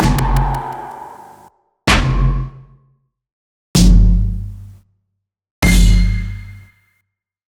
yell-sounds-hits-64bpm-2beats.ogg